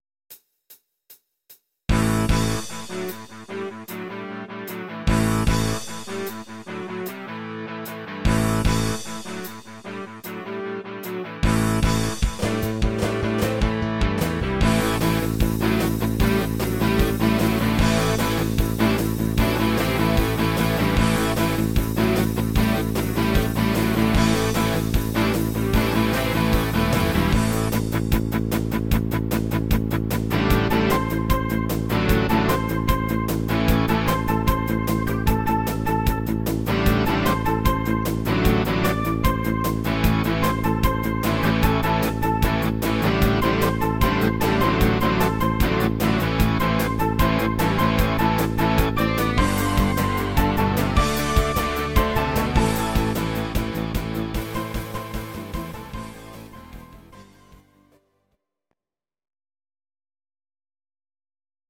Audio Recordings based on Midi-files
Pop, Rock, 2000s